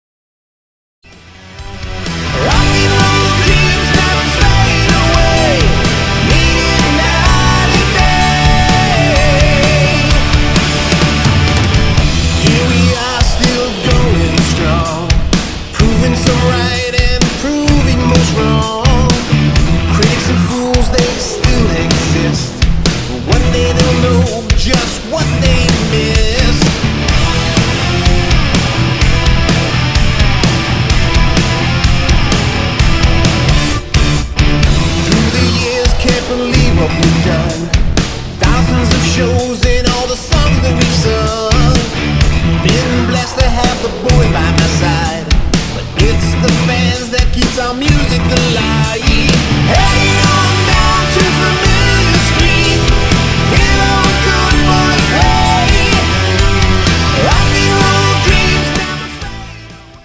Lead & Backing Vocals
Guitars
Drums & Percussion
Bass & Vocals
all keyboards, strings, etc